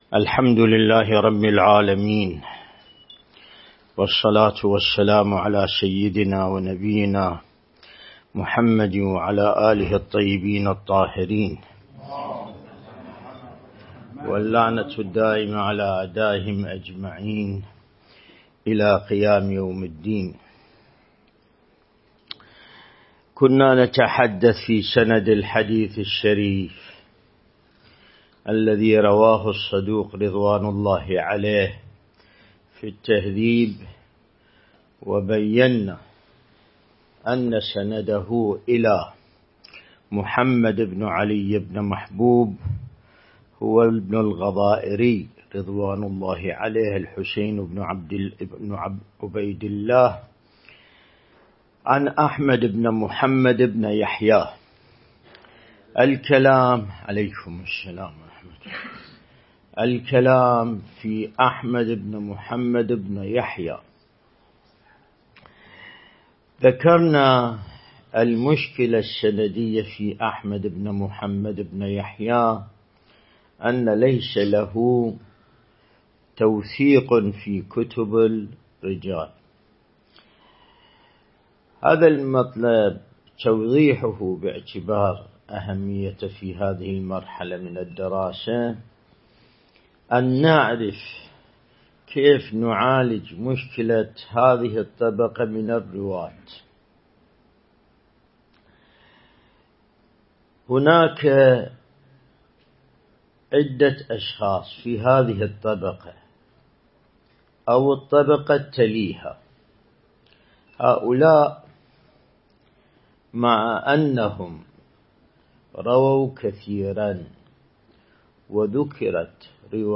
الدرس الاستدلالي